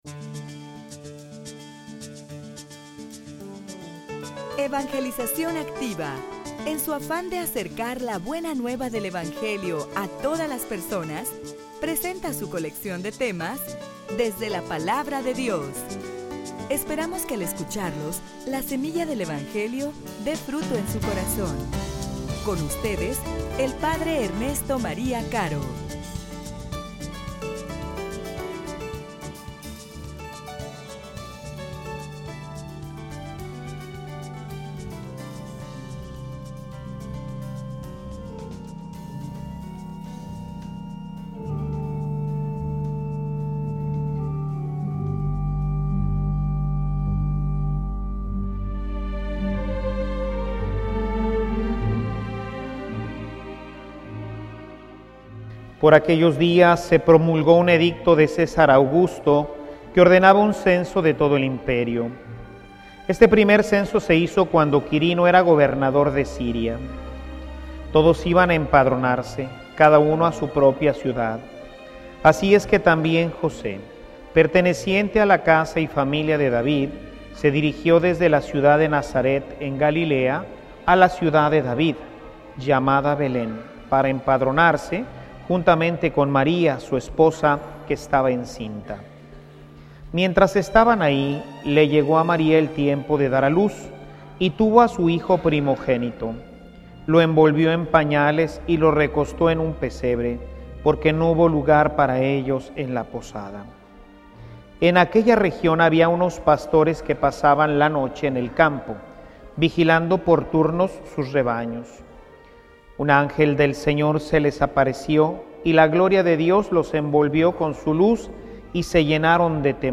homilia_Les_traigo_una_maravillosa_noticia.mp3